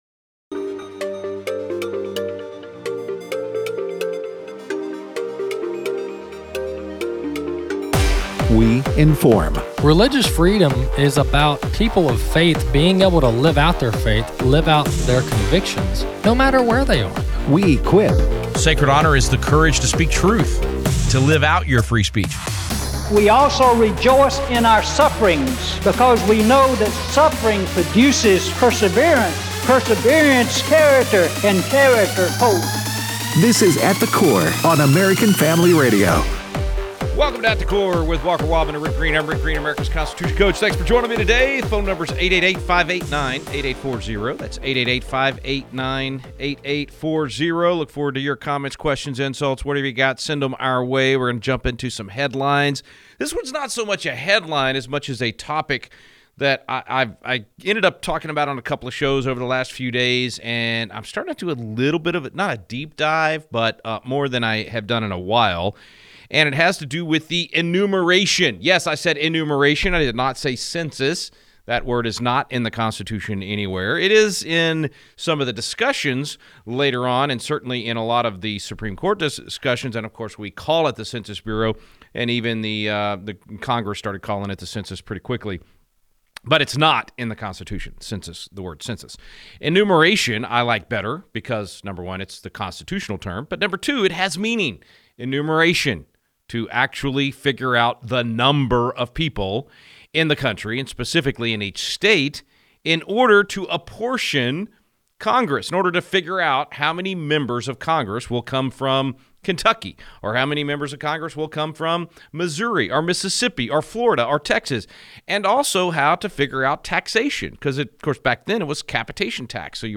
Callers continue to weigh in on their census interaction